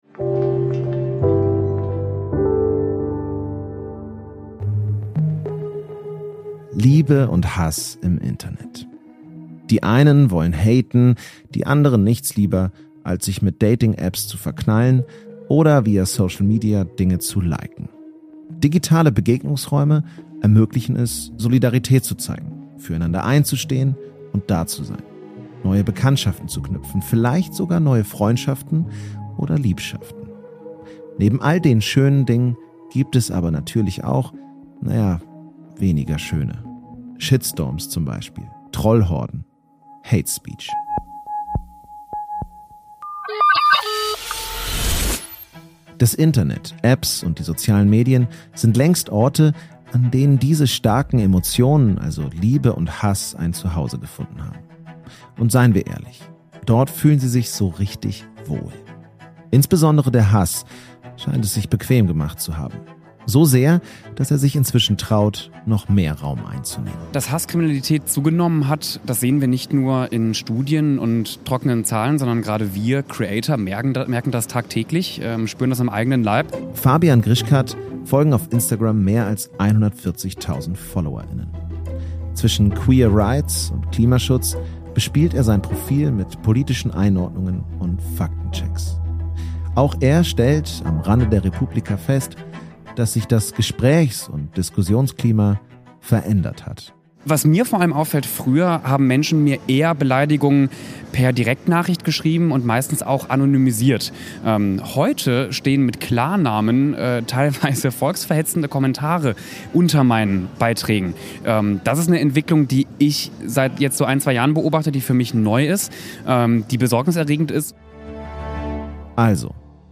Wir haben uns auf der re:publica Berlin umgehört, was gegen Hatespeech getan werden muss.